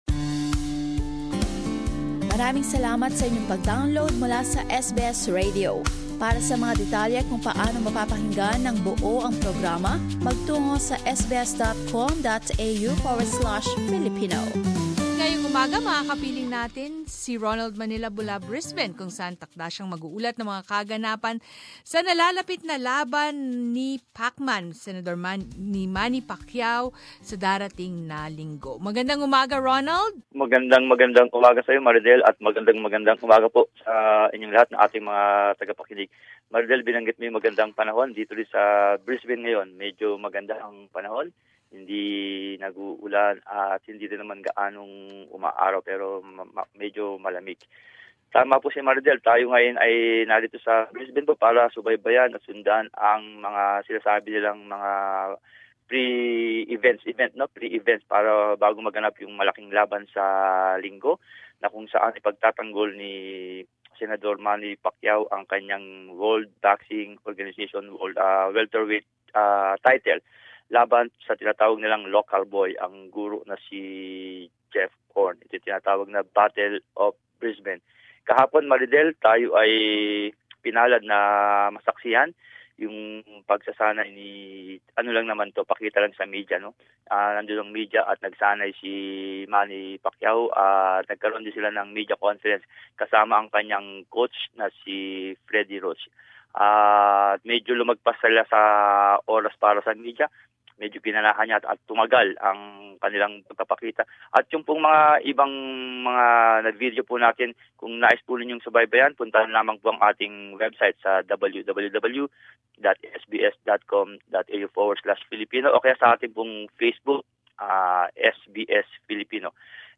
reports from Brisbane about the preparations for the coming Pacquiao -Horn match this coming Sunday, 2 July